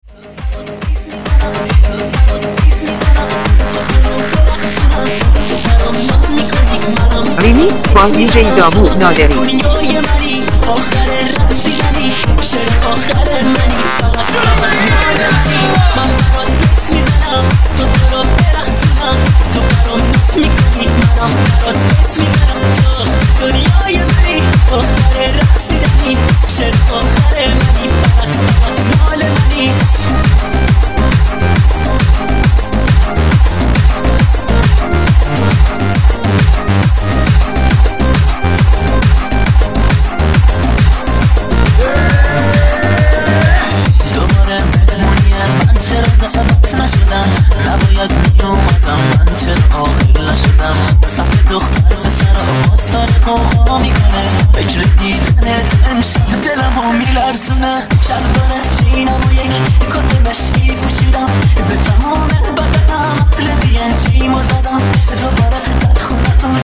ارکستی